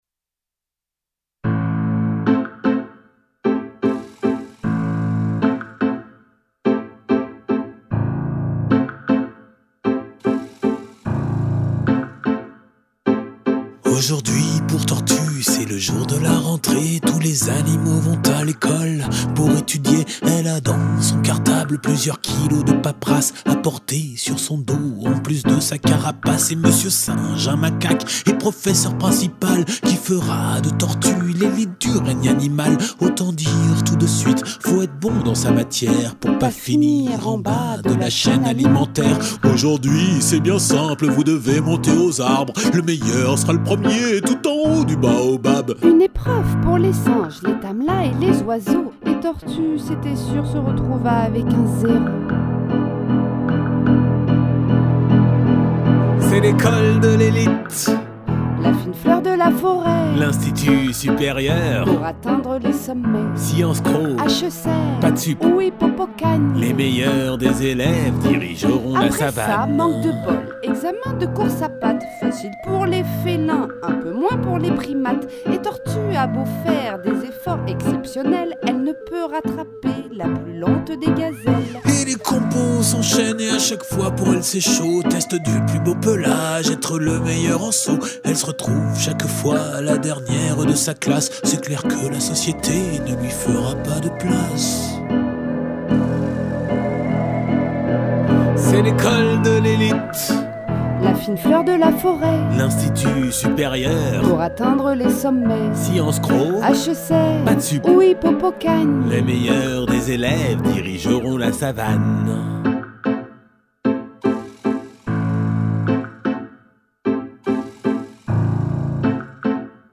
Musique pour enfant